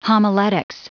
Prononciation du mot homiletics en anglais (fichier audio)
Prononciation du mot : homiletics